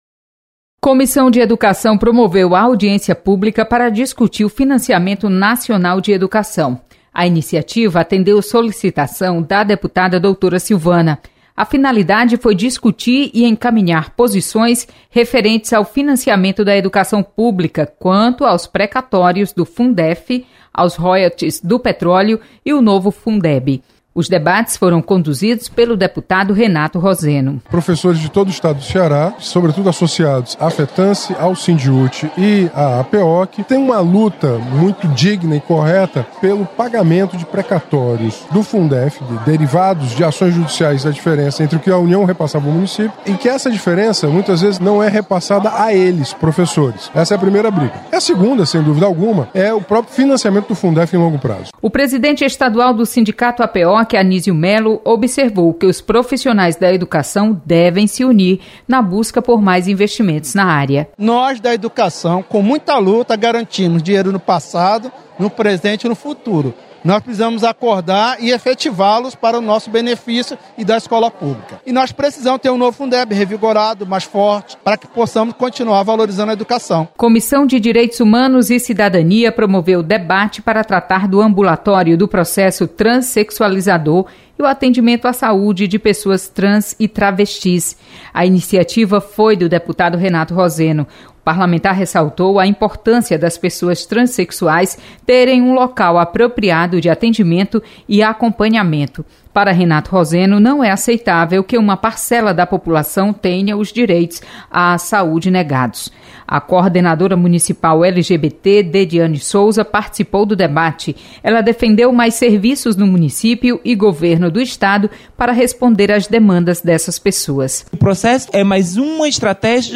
Acompanhe resumo das comissões técnicas permanentes da Assembleia. Repórter